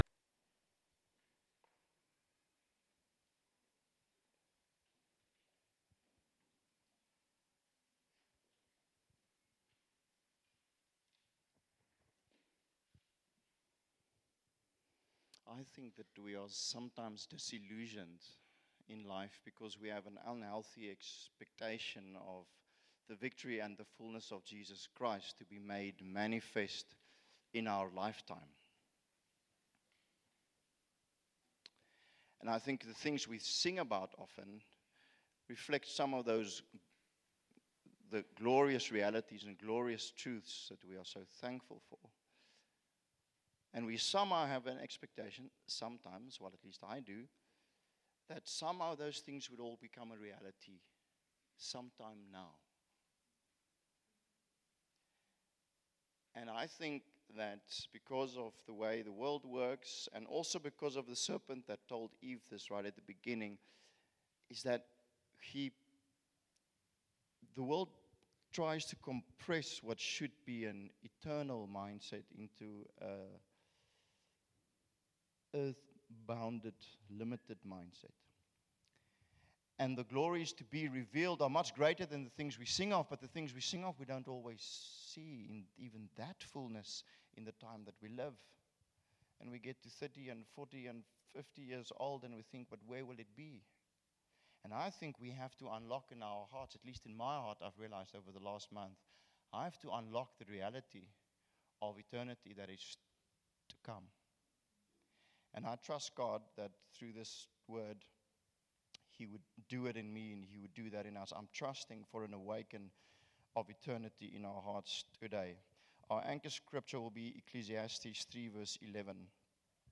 Sermons by Shofar Cape Town City